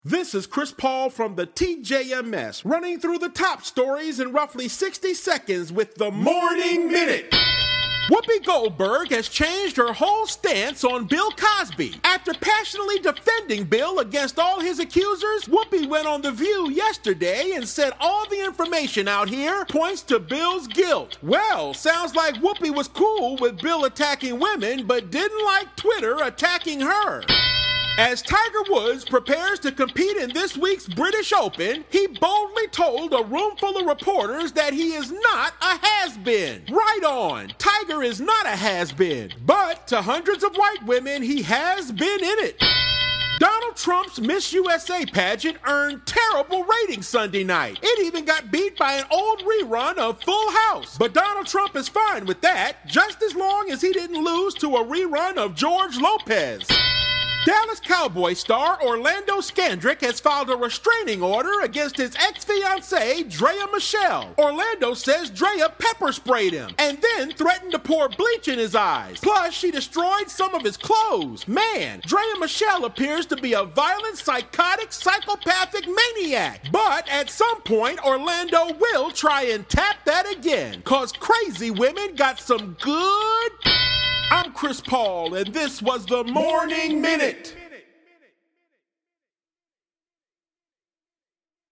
Comedian